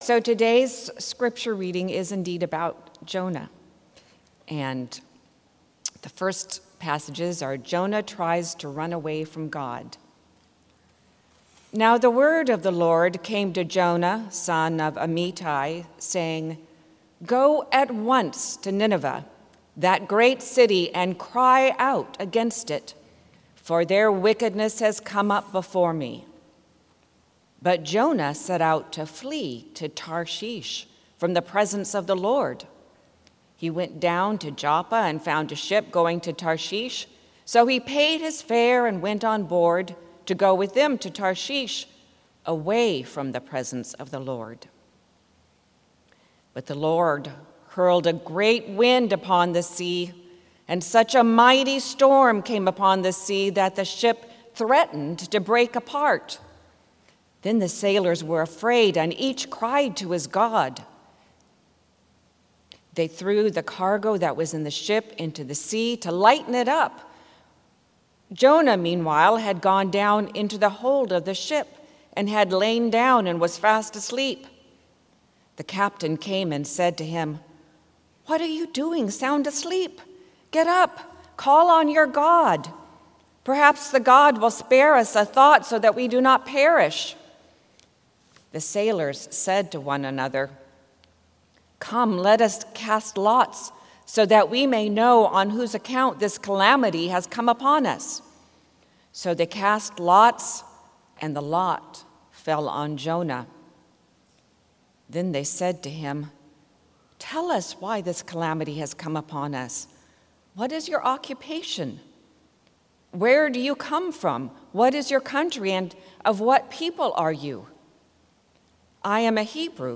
Sermon – Methodist Church Riverside